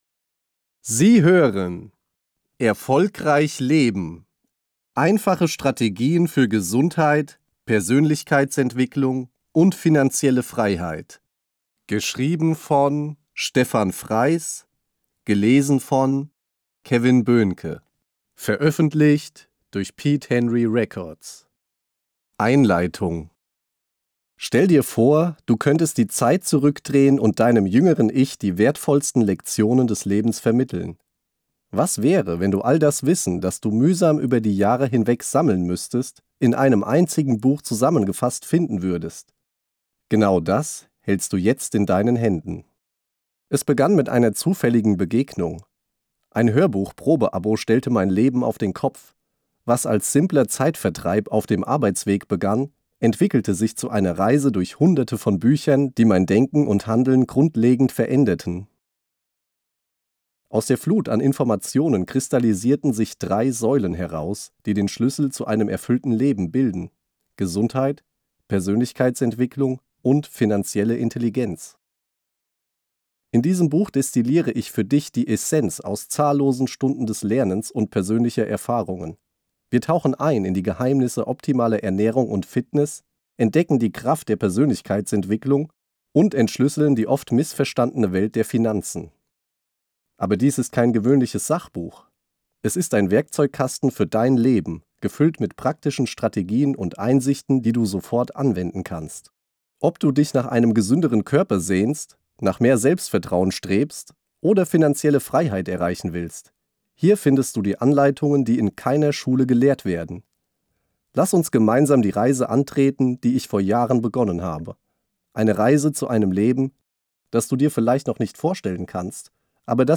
„Erfolgreich leben“ ist ein inspirierendes Hörbuch für alle, die ihr Leben bewusst gestalten und in Balance bringen möchten – körperlich, mental und finanziell.
Ein Hörbuch voller Klarheit, Motivation und Lebensfreude – ideal für alle, die bereit sind, Verantwortung für ihr Leben zu übernehmen und den nächsten Schritt zu gehen.